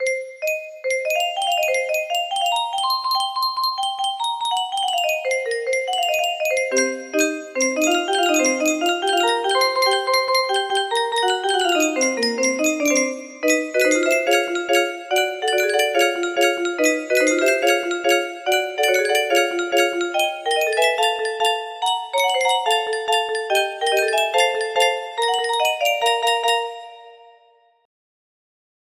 Agile Accelerando Music Box music box melody
Full range 60